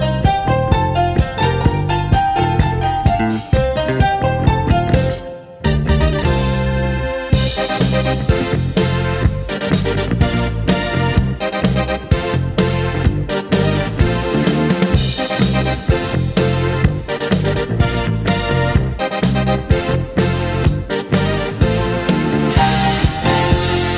Two Semitones Down Pop